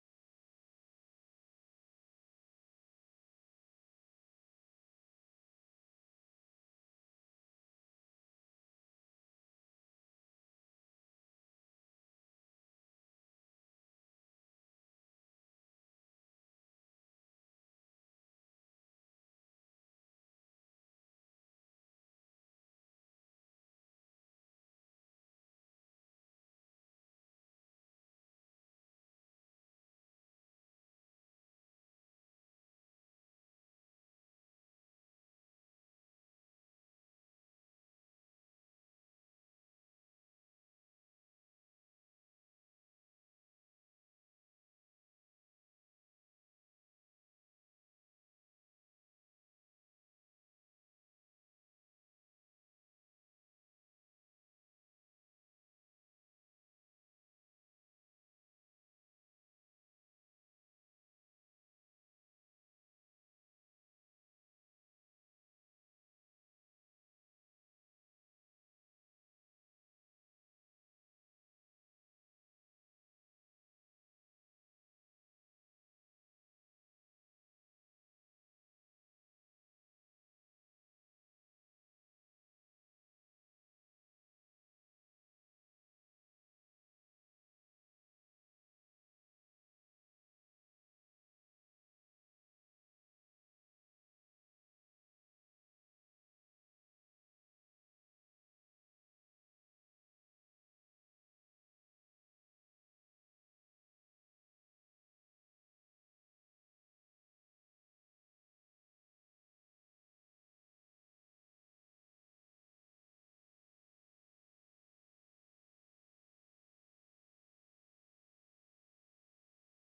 Family Worship Center Praise and Worship on July 13 2025